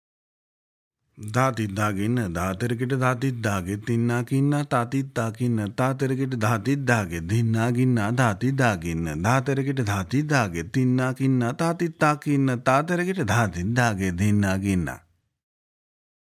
Demonstrations
Spoken – Medium